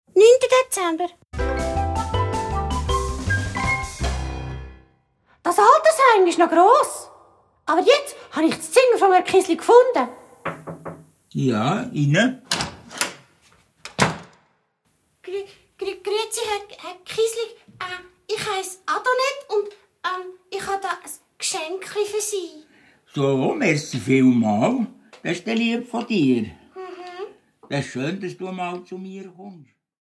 Hörspiel-Album (ohne Kalender)